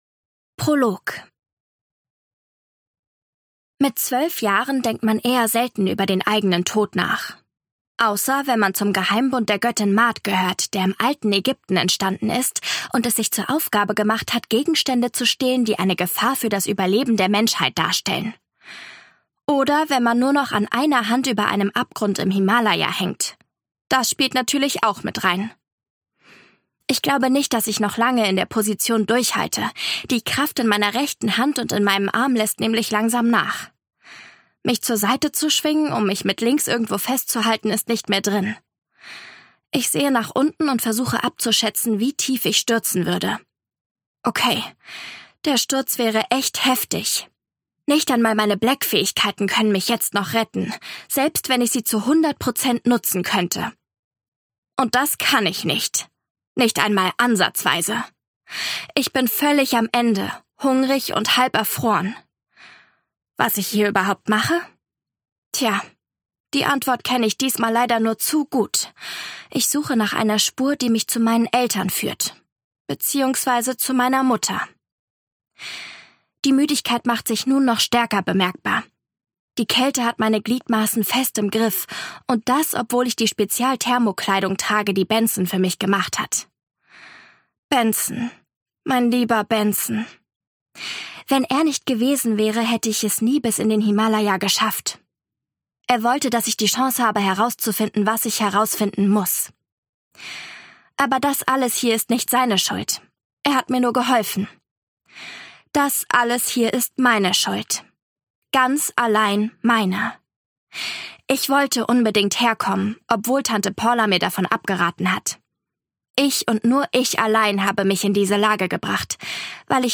Ungekürzte Lesung